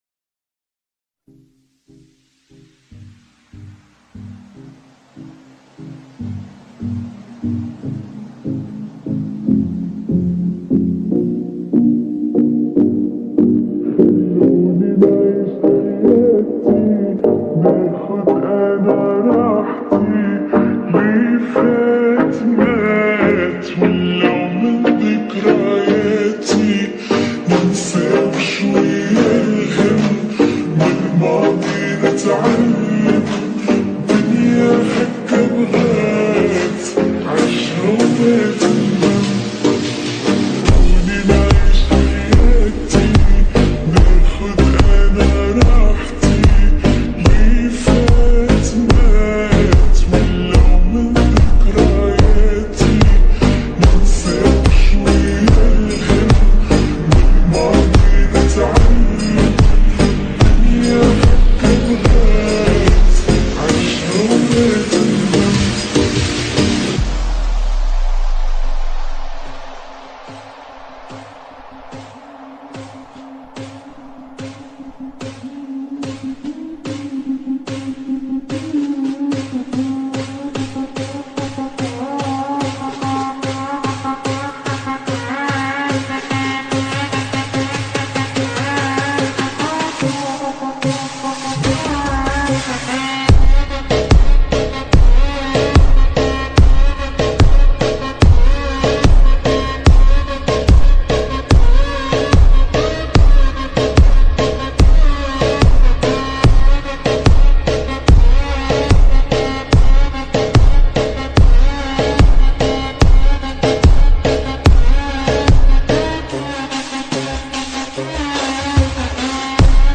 Remix Full Song
( Slowed+Reverb )